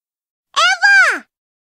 Grito de Eevee en la octava generación.ogg
Grito_de_Eevee_en_la_octava_generación.ogg